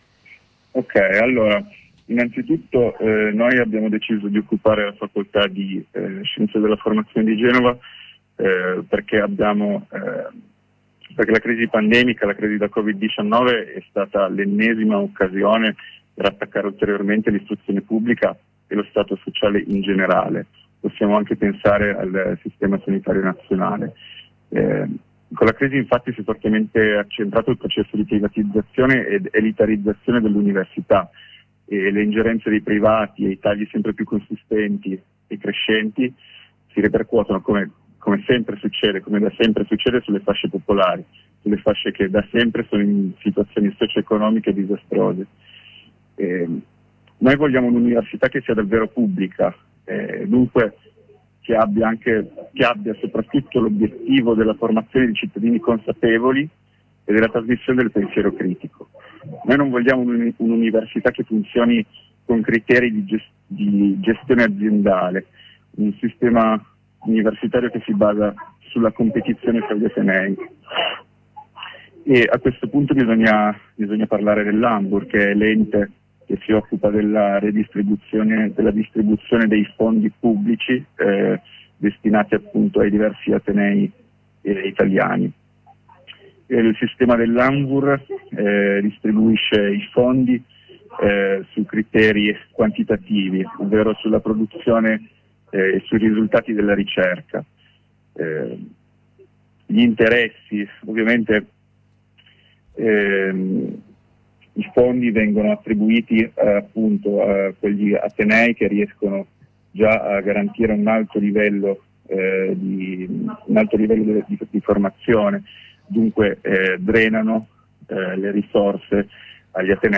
Il 19 aprile è stata occupata una facoltà dell’Università di Genova sia per tornare a vivere l’Università come spazio fisico e come luogo di cultura e dove intessere relazioni, sia per opporsi al modello di università-azienda che mette al centro più la performatività degli atenei e la loro posizione in graduatorie dai criteri discutibili che la qualità dell’insegnamento e della ricerca. Ne abbiamo parlato con un compagno del collettivo di Scienze della Formazione.
Ascolta la diretta: